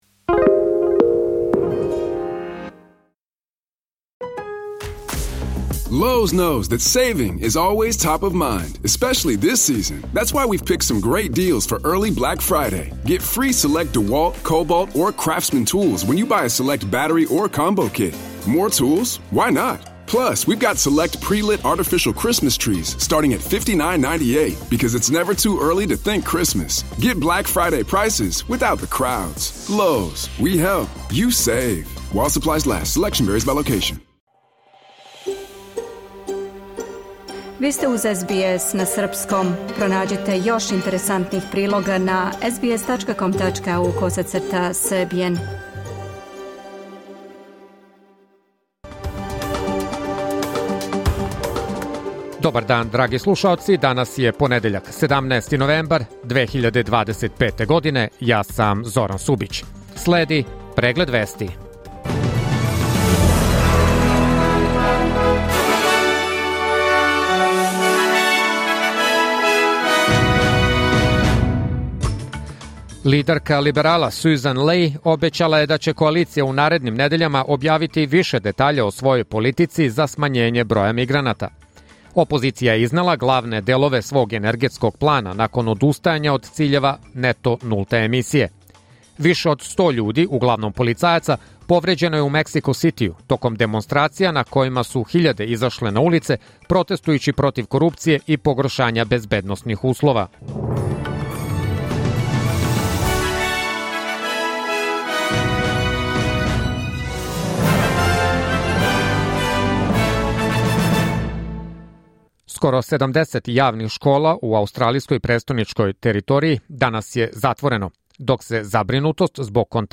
Serbian News Bulletin Source: SBS / SBS Serbian